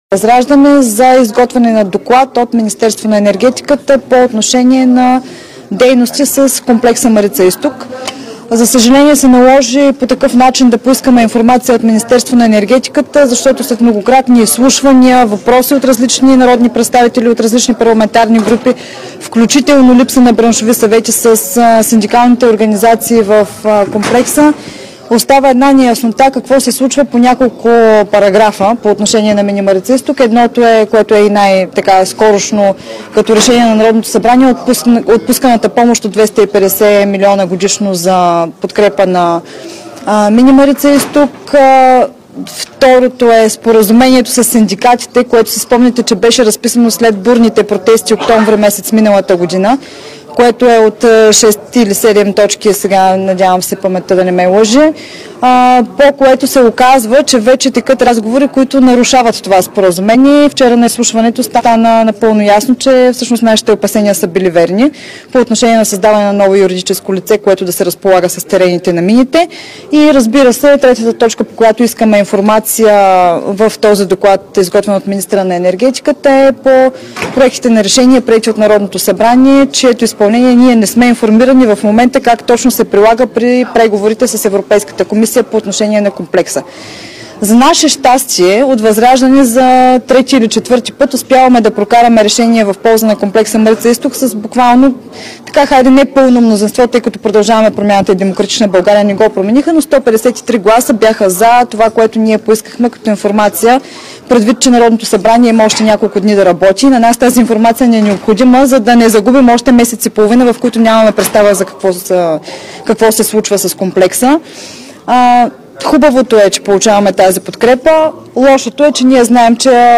9.05 - Заседание на Народното събрание.
- директно от мястото на събитието (Народното събрание)
Директно от мястото на събитието